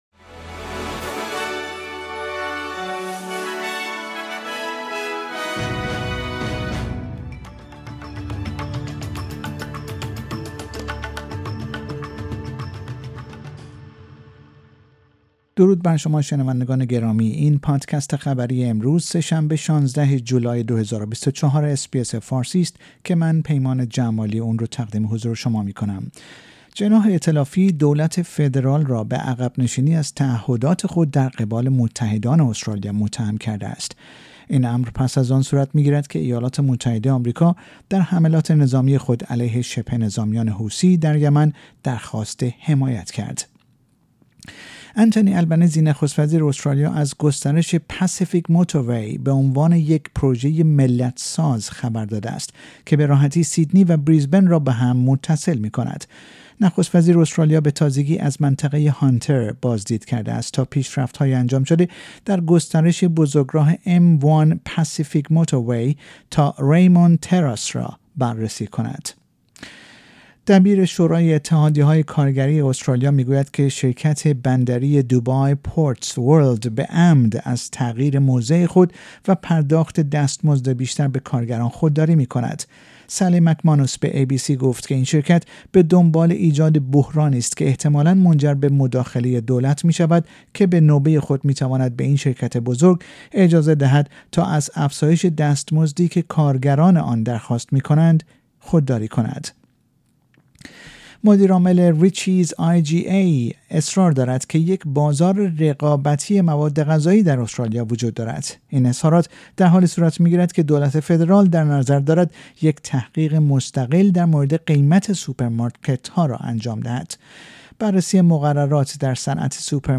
در این پادکست خبری مهمترین اخبار استرالیا و جهان در روز سه شنبه ۱۶ ژانویه ۲۰۲۴ ارائه شده است.